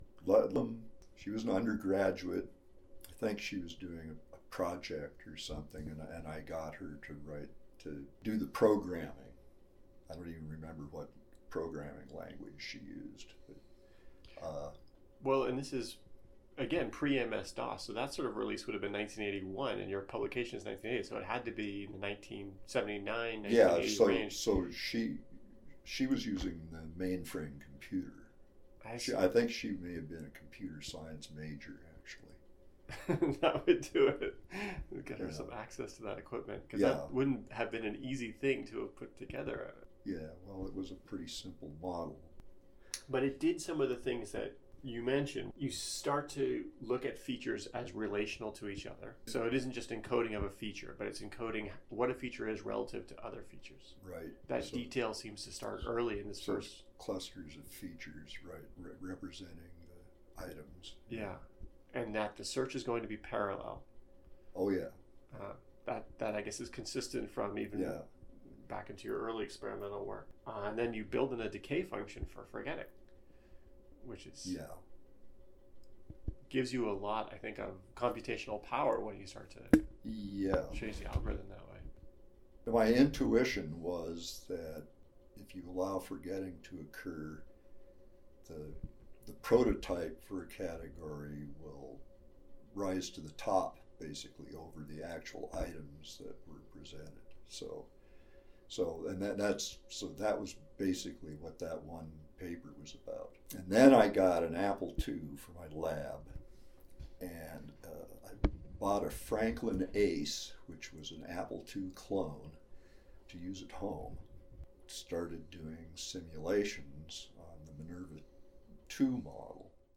He describes the creation and testing of Minerva 2 in the following excerpt from our conversation: